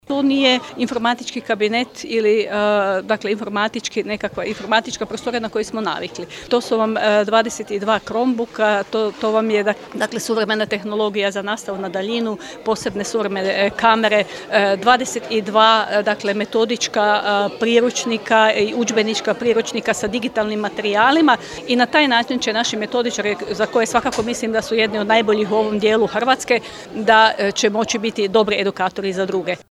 U nju je kroz javno-privatno partnerstvo uloženo 200 tisuća kuna i nastavak je ulaganja u digitalizaciju Učiteljskog fakulteta, moglo se čuti na prigodnoj svečanosti koja je bila i prilika za podjelu zahvalnica.